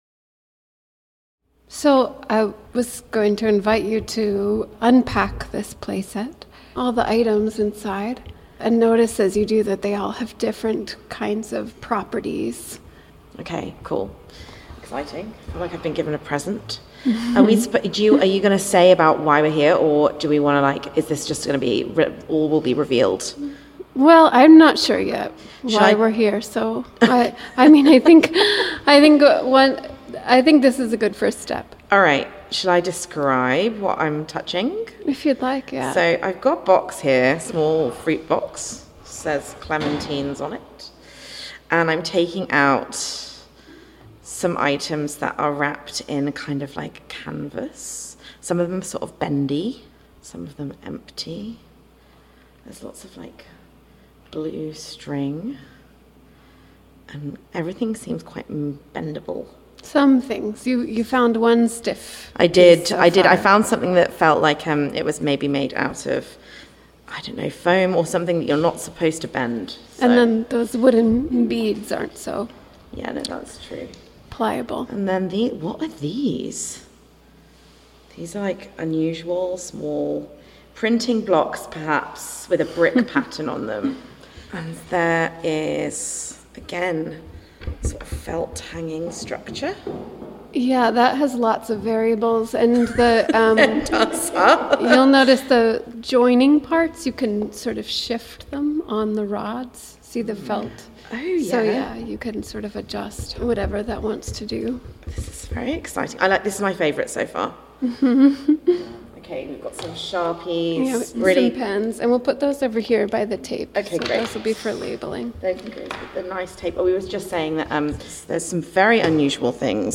The listener is not addressed; they listen in on a conversation.